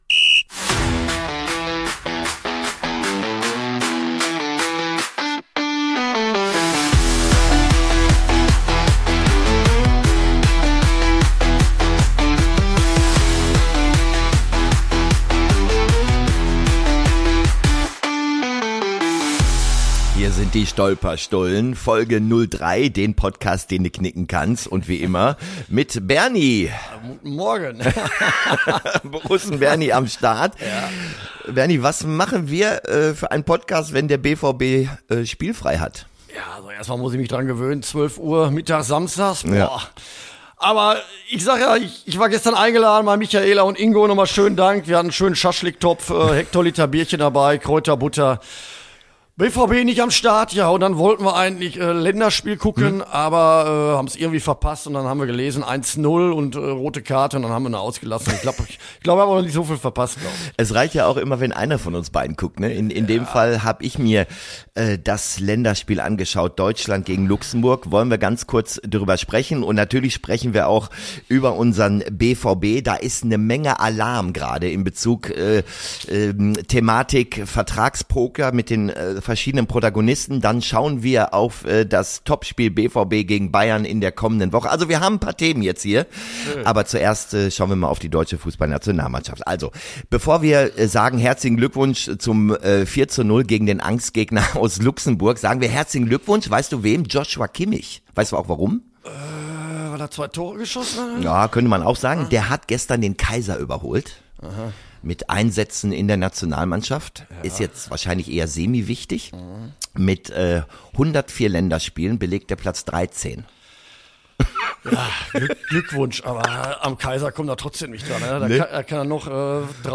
Außerdem diskutieren die beiden, was es bedeutet, dass Spiele der spanischen und italienischen Ligen künftig auch im Ausland ausgetragen werden dürfen – Fußball global oder nur noch Kommerz? Humor, Herzblut und ehrliche Meinung – so wie’s nur im Ruhrpott klingt!